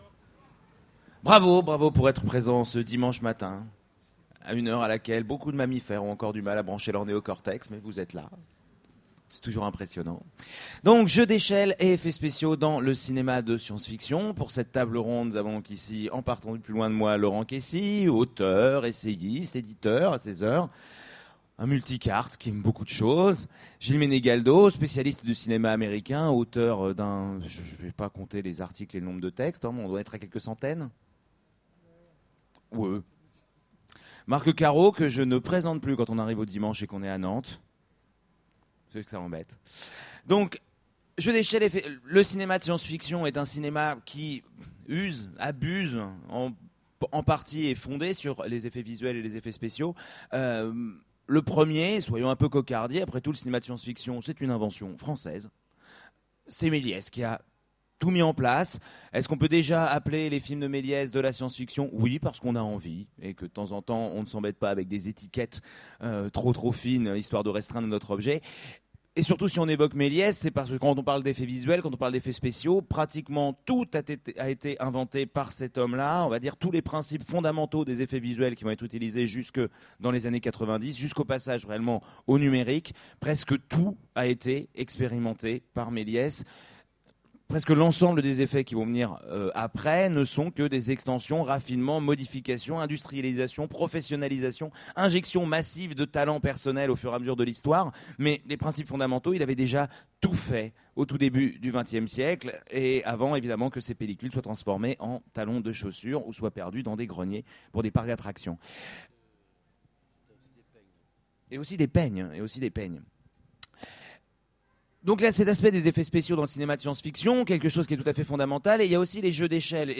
Utopiales 13 : Conférence Effets spéciaux et jeux d'échelle dans le cinéma de science-fiction